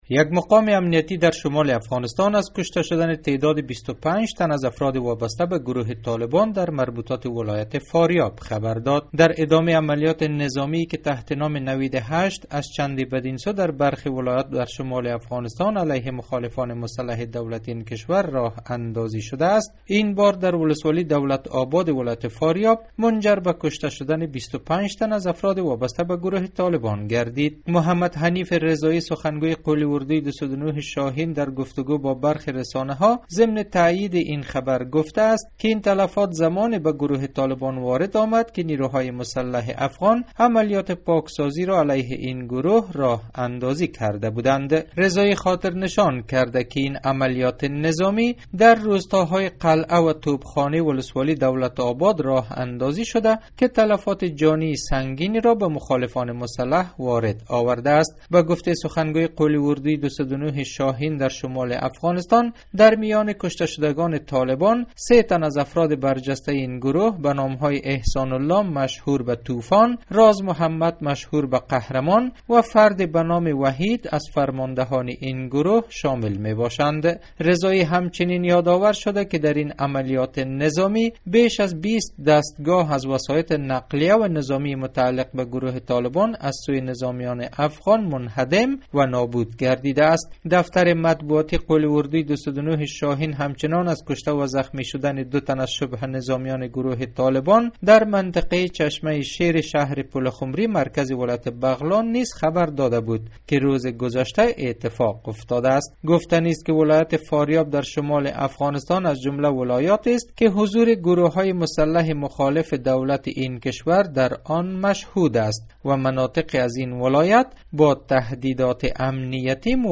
گزارش : هلاکت 25 تن از نیروهای طالبان در ولایت فاریاب